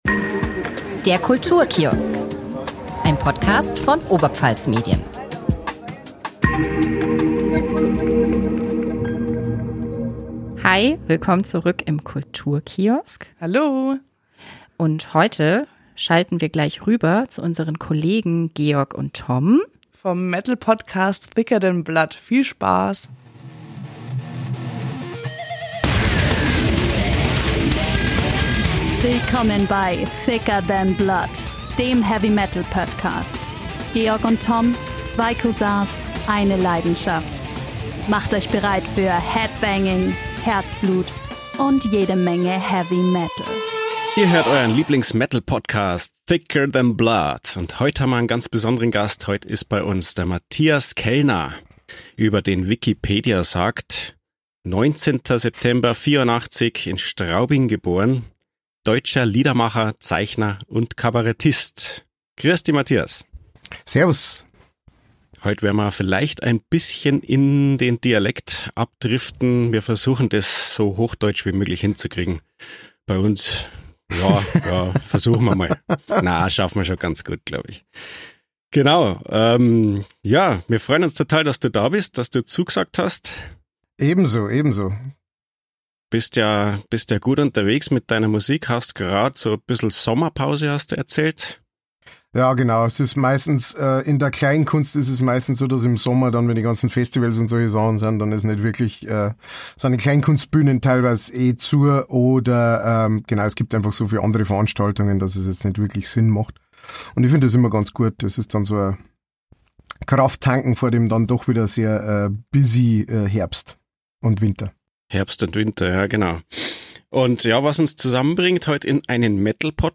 12.04.2024 – Langsam Gesprochene Nachrichten